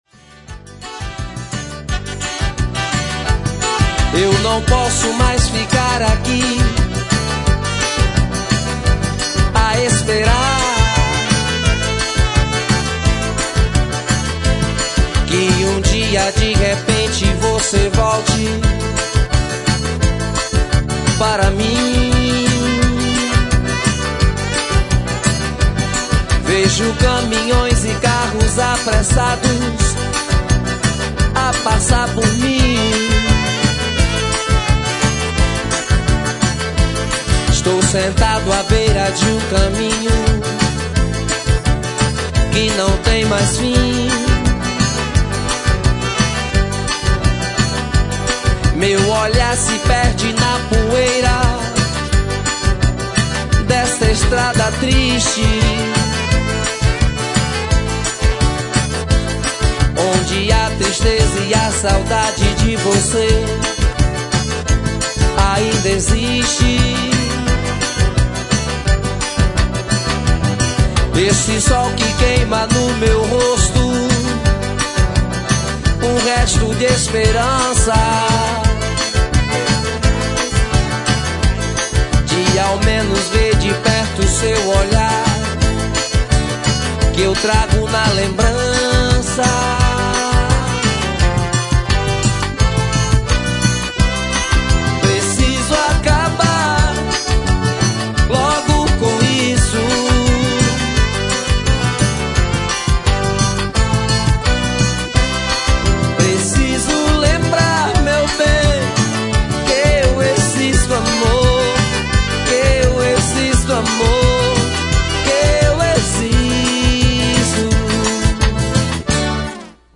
Forro